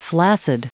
Prononciation du mot flaccid en anglais (fichier audio)
flaccid.wav